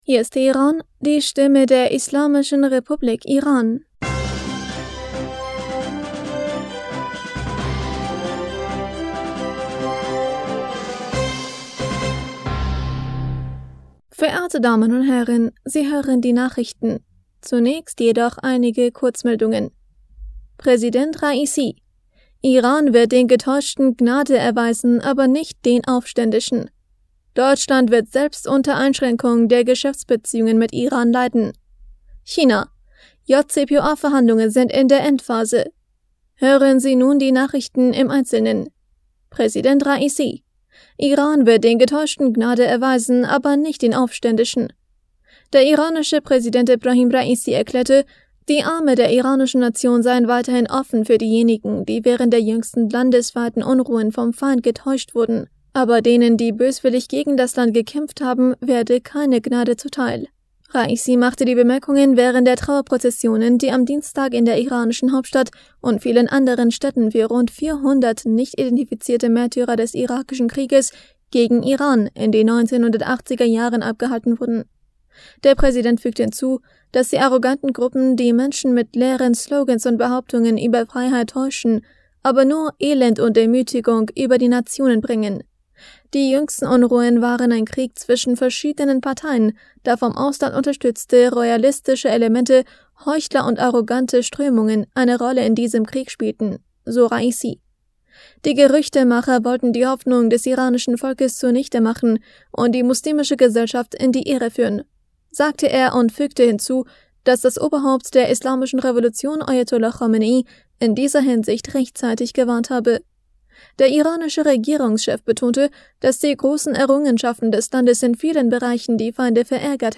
Nachrichten vom 27. Dezember 2022